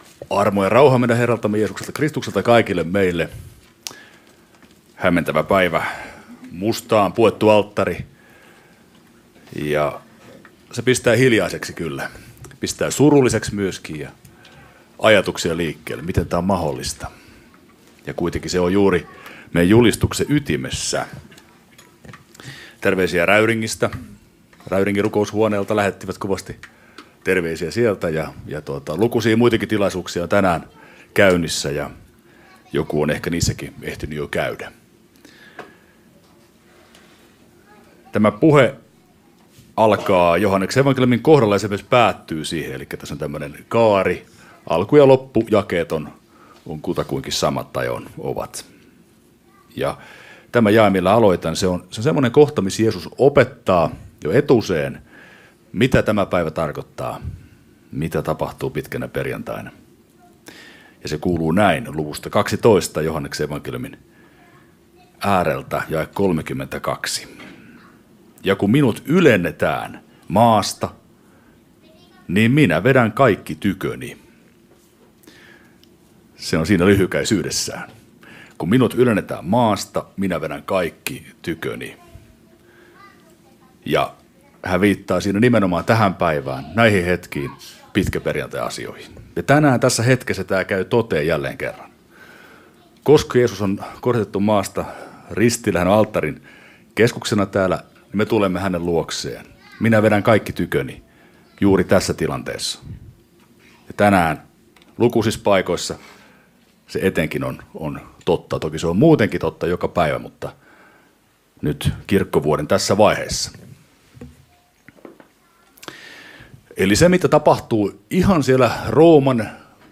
Kokkolassa ristinjuhlassa pitkäperjantaina Tekstinä Joh. 12:32